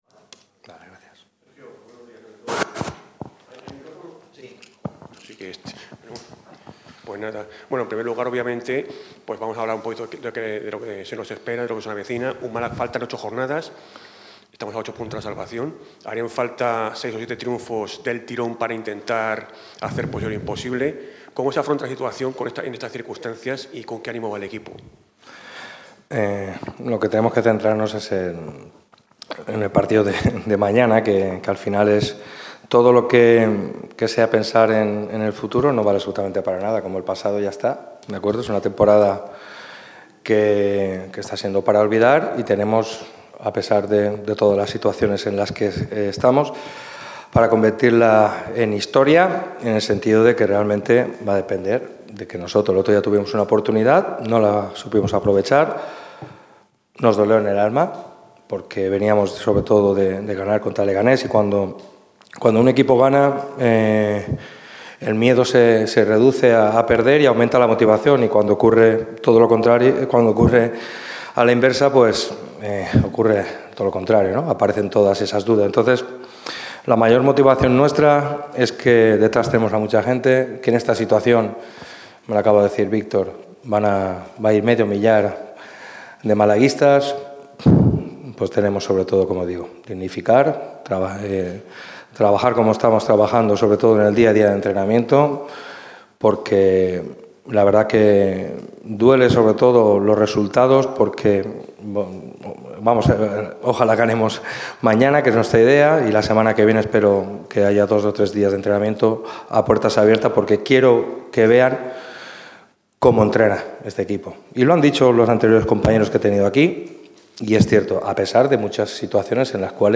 Este jueves ha hablado el técnico Sergio Pellicer. El entrenador malaguista pasó por sala de prensa en la comparecencia previa al Villarreal 'B'-Málaga.